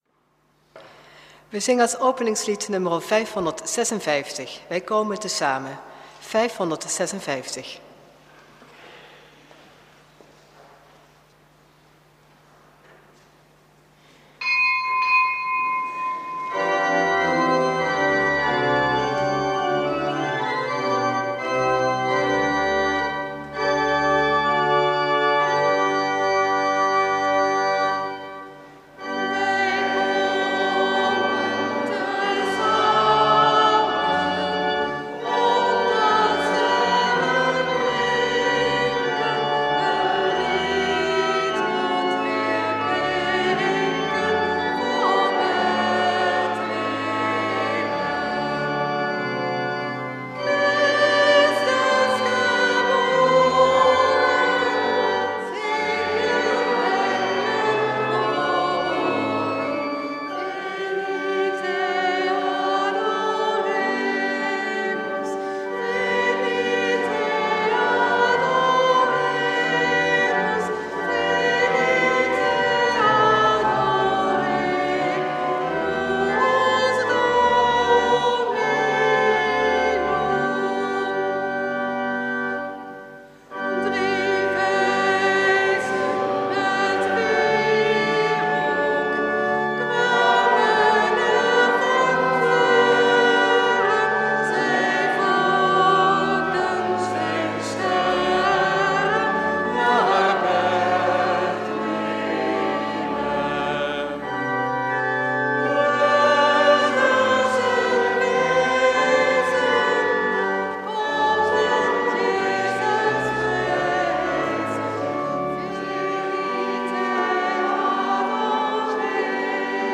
Eucharistieviering beluisteren (MP3)